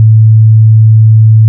Farming (8): plant_seed, water_crop, harvest, dig, scythe, mine, chop, cow
**⚠  NOTE:** Music/SFX are PLACEHOLDERS (simple tones)
cow_moo.wav